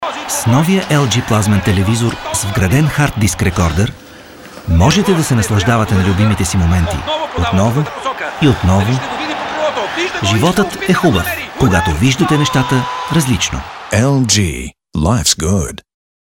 Sprecher bulgarisch für TV / Rundfunk / Industrie / Werbung.
Sprechprobe: Industrie (Muttersprache):
bulgarian voice over artist